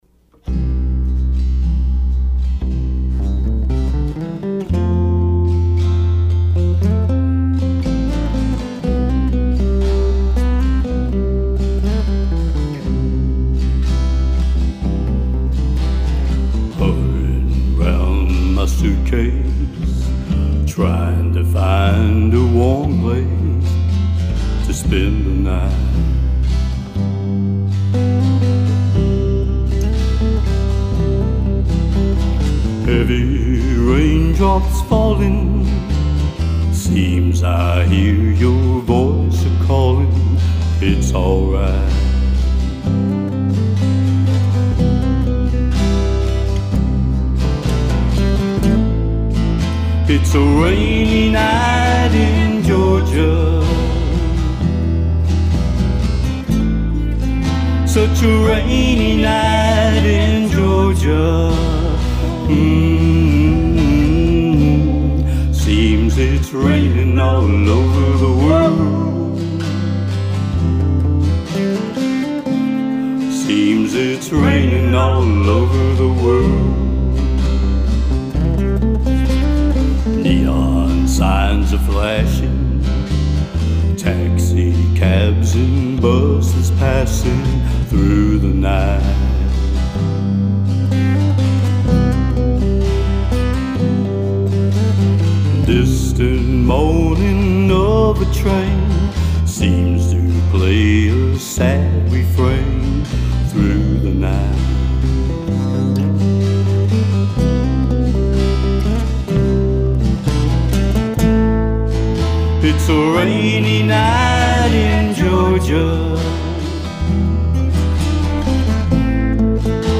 Traditional acoustic music, live and in person.